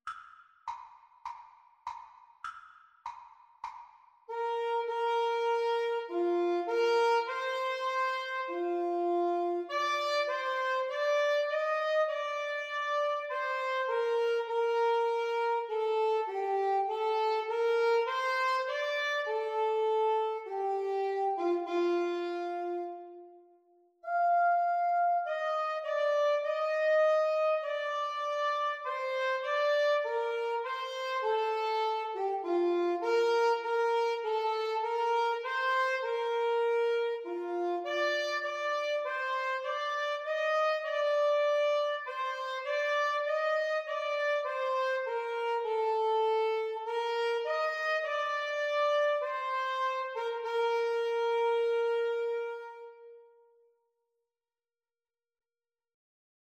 Free Sheet music for Alto-Tenor-Sax Duet
4/4 (View more 4/4 Music)
Bb major (Sounding Pitch) (View more Bb major Music for Alto-Tenor-Sax Duet )